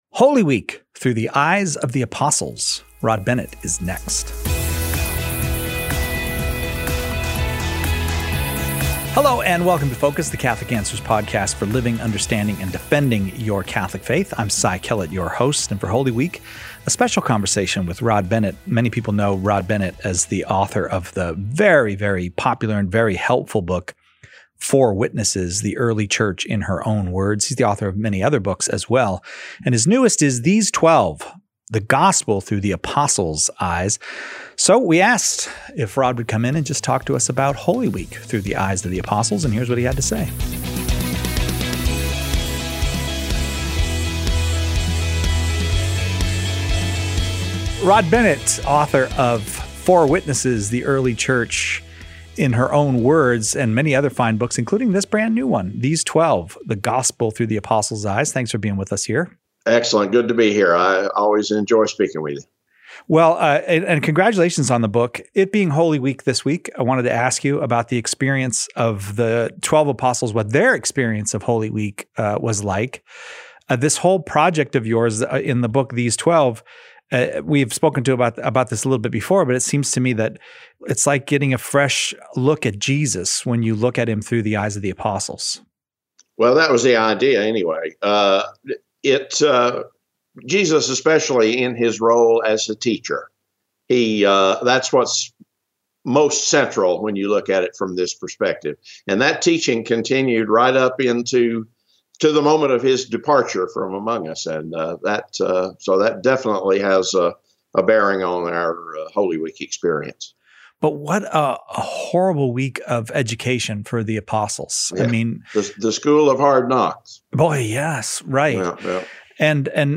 a special conversation